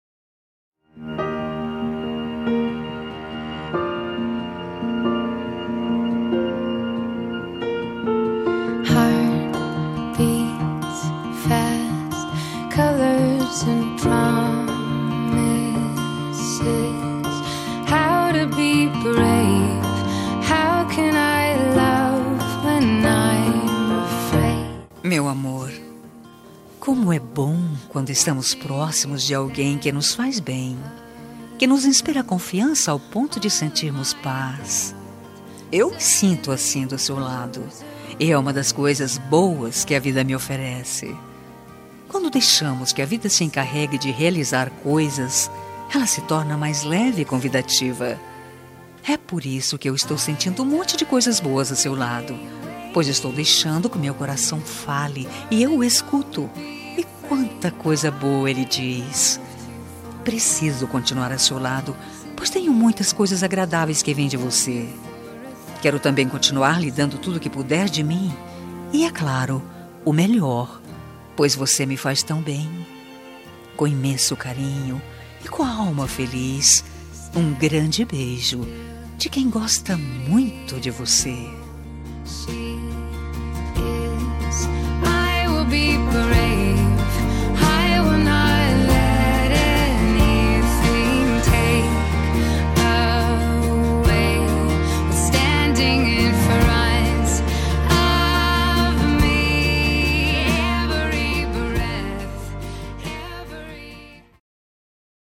Telemensagem Amante – Voz Feminina – Cód: 5400